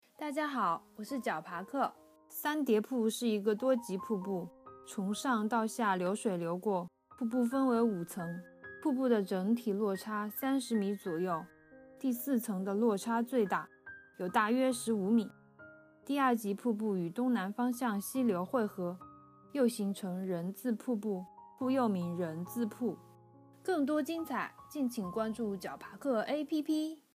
三叠瀑----- fin 解说词: 三叠瀑位于景区东部丫山村至龙山村公路西侧，海拔103.5米，整体落差约30米，共分五级，第一级瀑布宽约4米，落差约5米，第二季瀑布落差约6米，第三级瀑布落差约3米，四级、五级瀑布的落差分别为15米和2米。